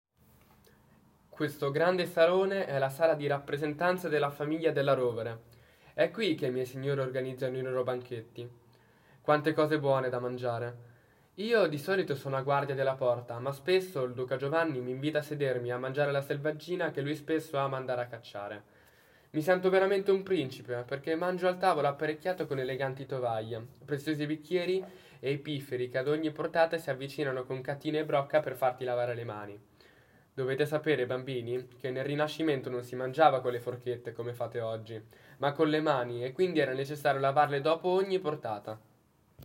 Audioguida 0-12
Ascolta Riccardino, il cavaliere della Rocca